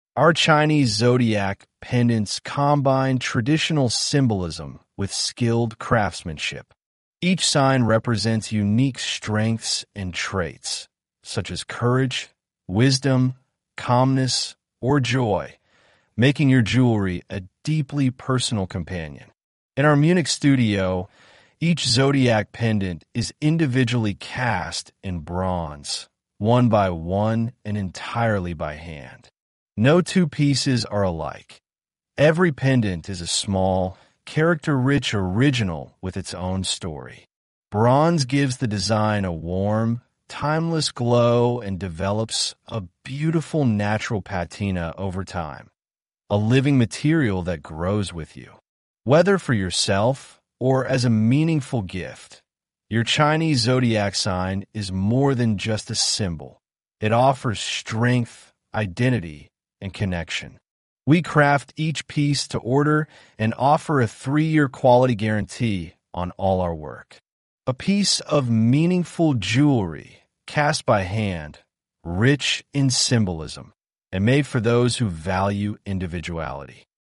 ChiTierkreis-engl-ttsreader.mp3